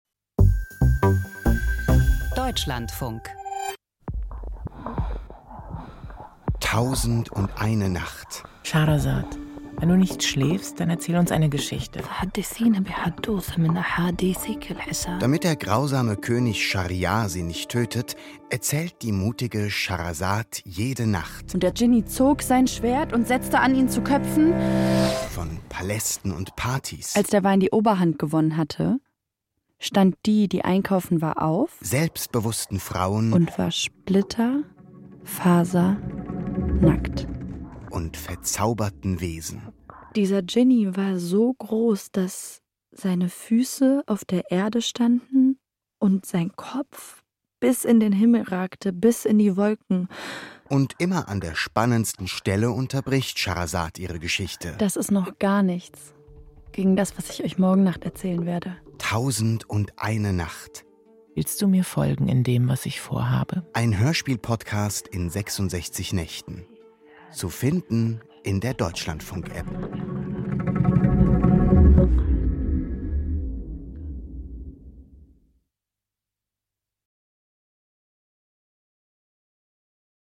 Schahrasad erzählt um ihr Leben – und wir hören zu. Diese Hörspiel-Adaption des neu übersetzten Literaturklassikers “1001 Nacht” führt uns in Paläste und auf Partys, nach Kairo und Bagdad, zu selbstbewussten Frauen und verzauberten Wesen.